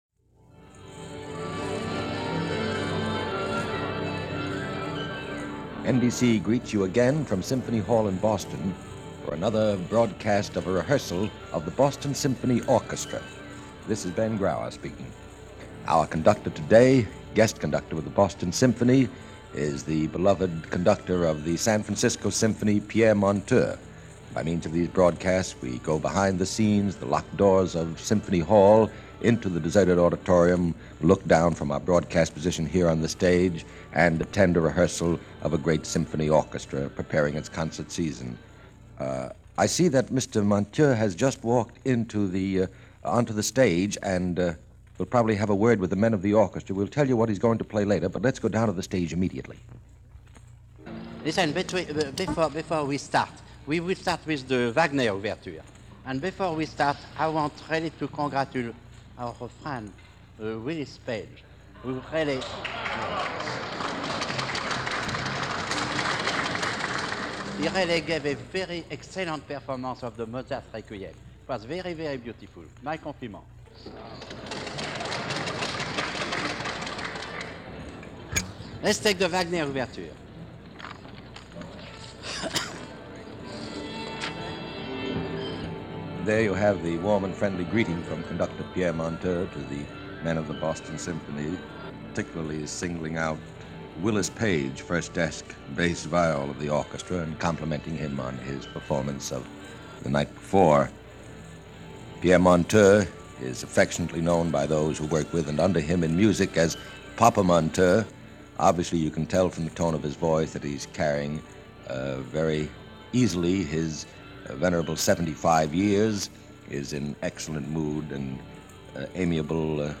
NBC Radio – Boston Symphony In Rehearsal – Pierre Monteux, guest Conductor – February 3, 1951 – Gordon Skene Sound Collection –
This week it’s then-Music Director of the San Francisco Symphony Pierre Monteux leading the orchestra in a rehearsal of the Wagner Flying Dutchman overture and the Brahms Symphony Number 3.
Boston-Symphony-Monteux-Rehearsal-Feb.-5-1951.mp3